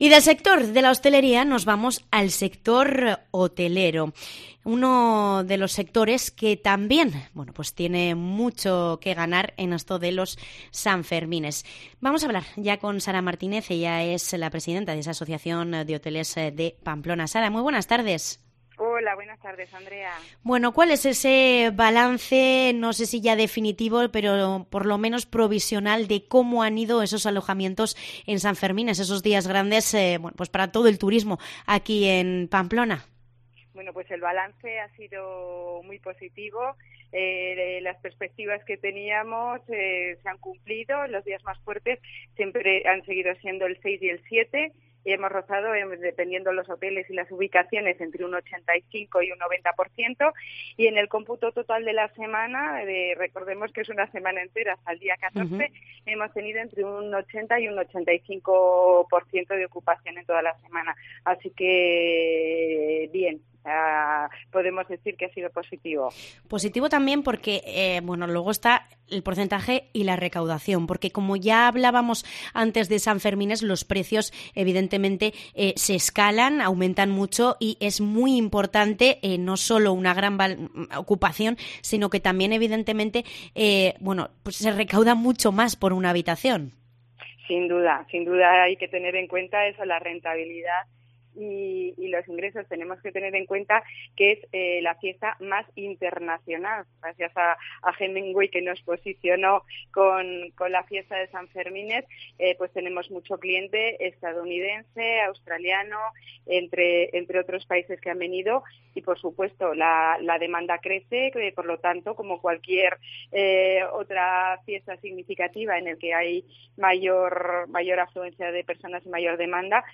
Entrevista balance hotelero San Fermín